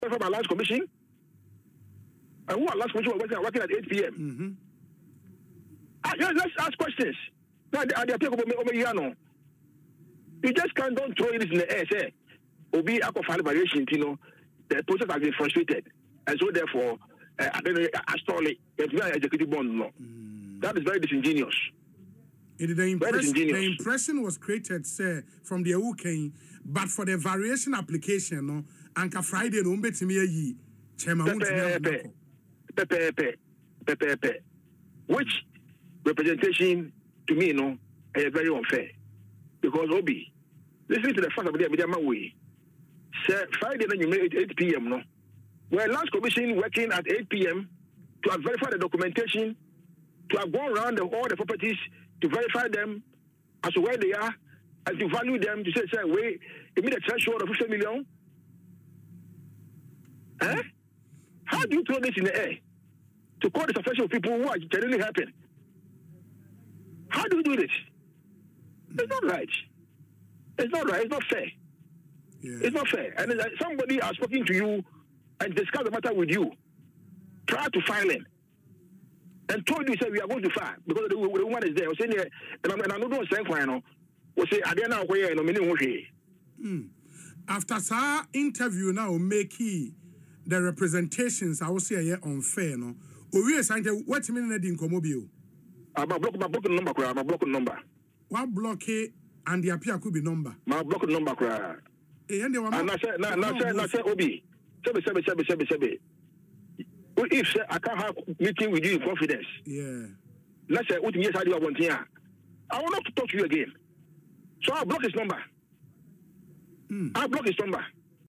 He made the disclosure on Asempa FM’s Ekosii Sen,